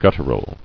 [gut·tur·al]